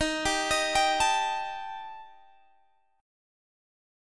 D#Mb5 chord